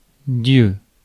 Prononciation
Synonymes seigneur créateur divinité démiurge idole déité légende Prononciation France: IPA: [djø] Quebec: IPA: /dzjø/ Le mot recherché trouvé avec ces langues de source: français Traduction Substantifs 1.